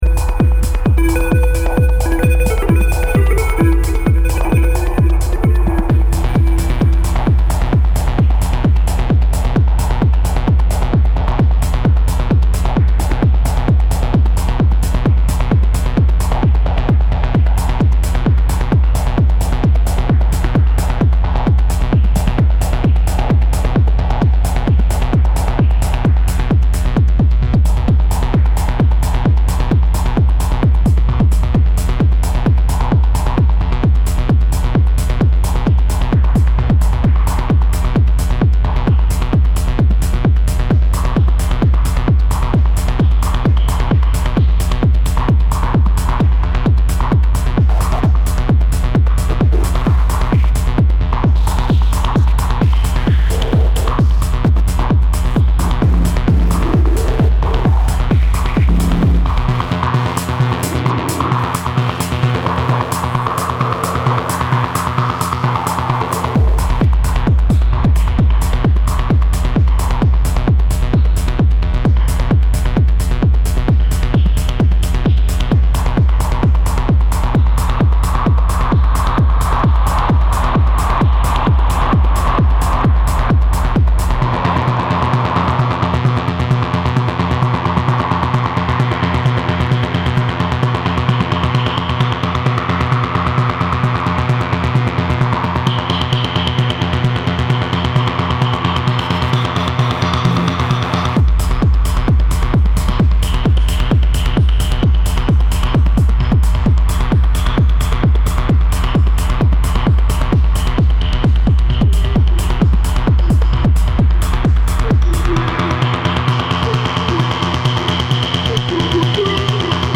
keeping it at 131bpm
Really like the hats/clap